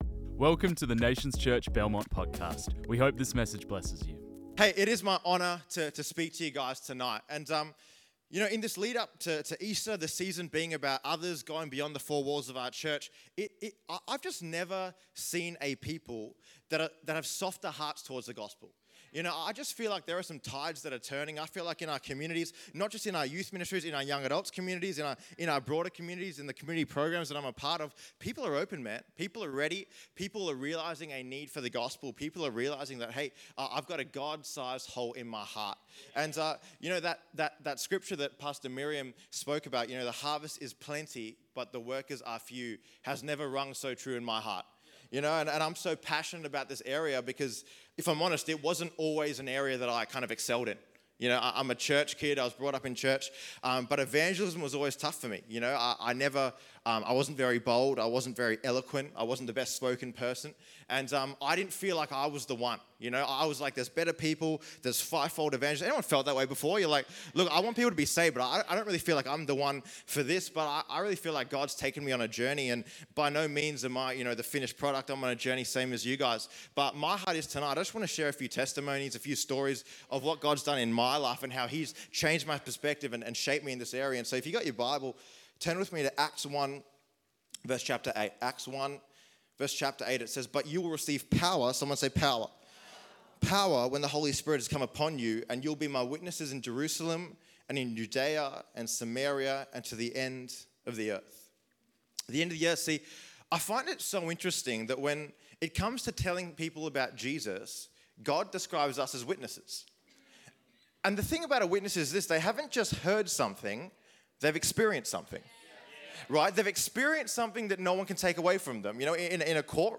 This message was preached on 13 April 2024.